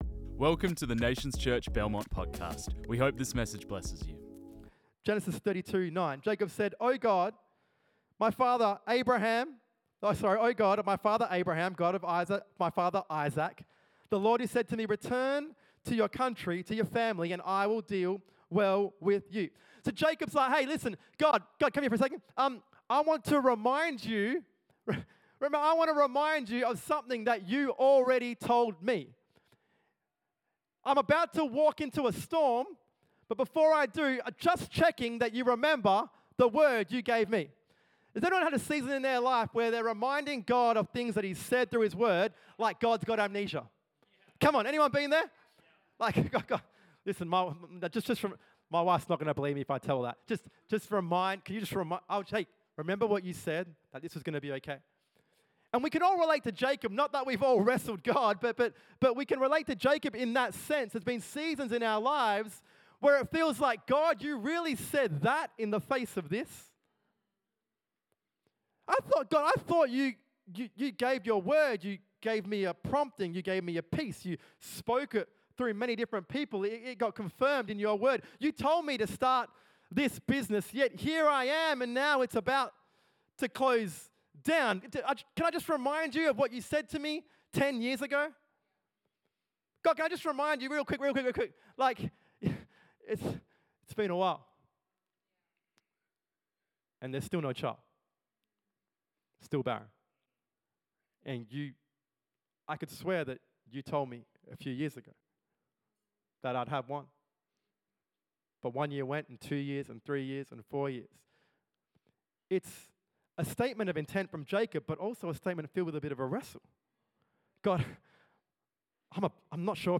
This message was preached on 22 June 2025.